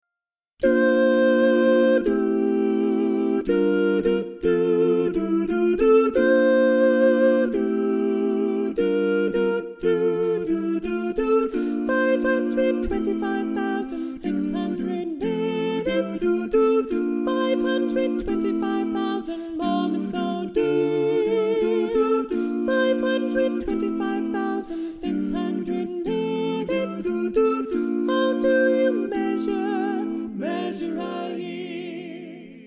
SSAA